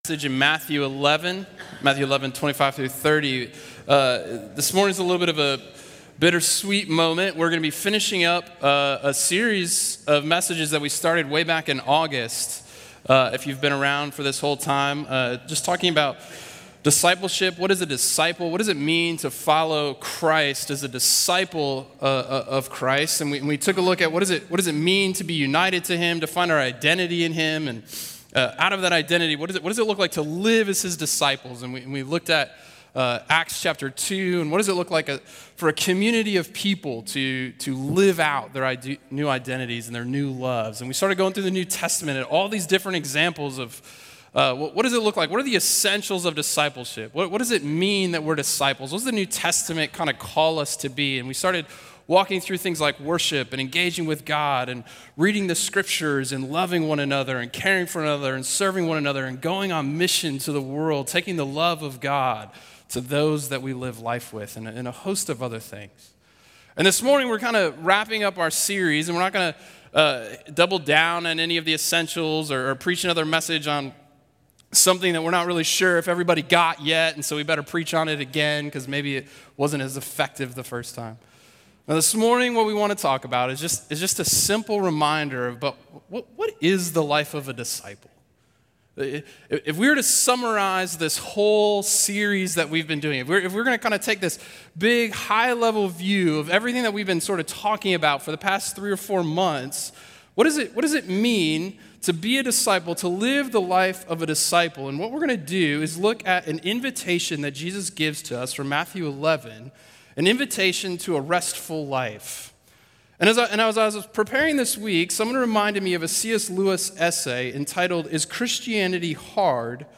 A message from the series "Legacy."